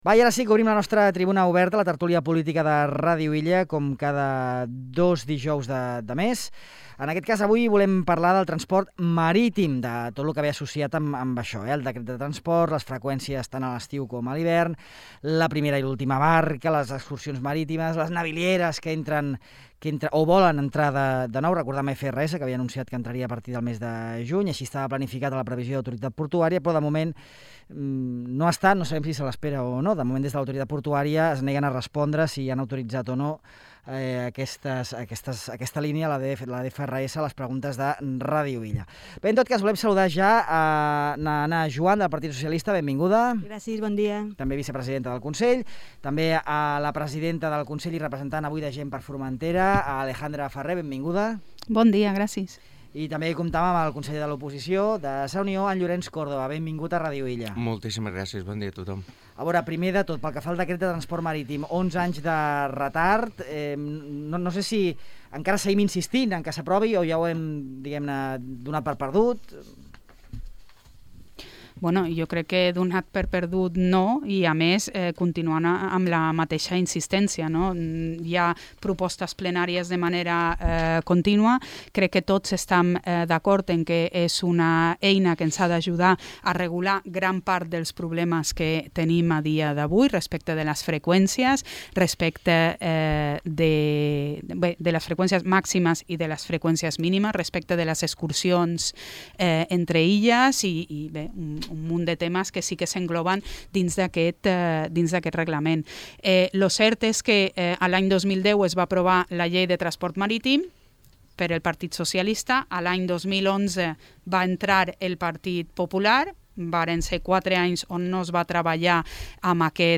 La línia pitiüsa i el transport marítim a Formentera, a la tertúlia política